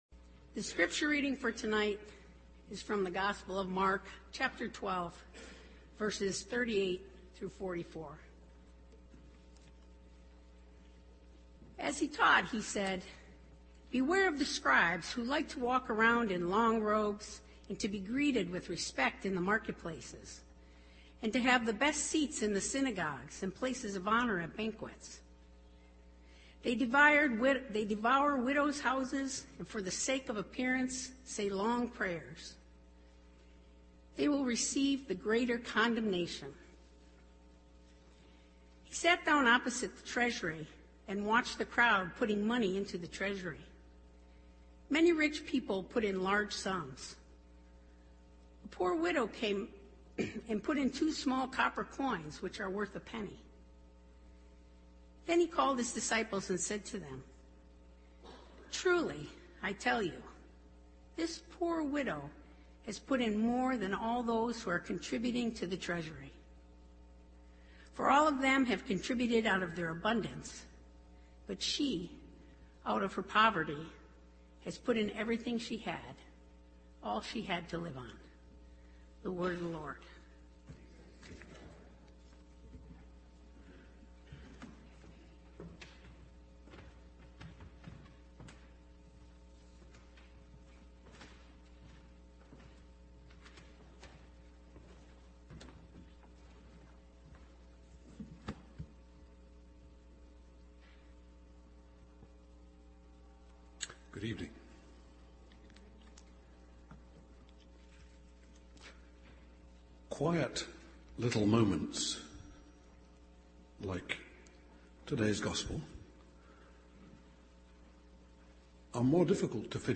Sermons .